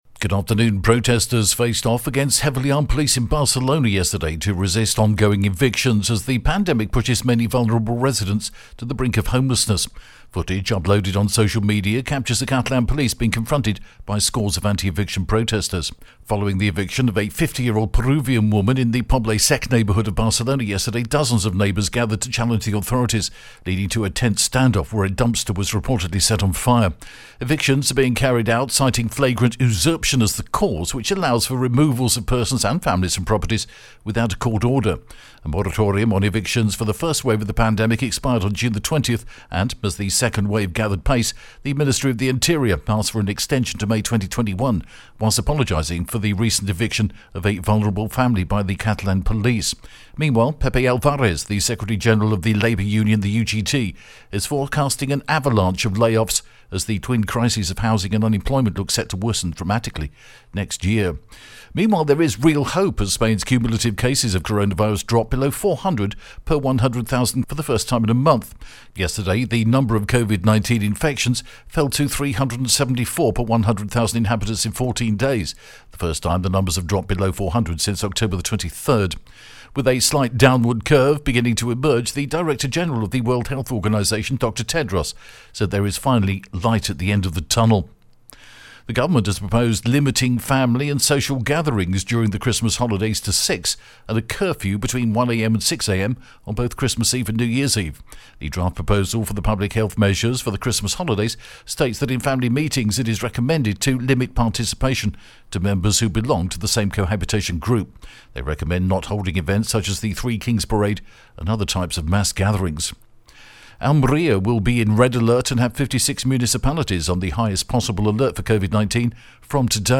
The latest Spanish news headlines in English: November 24th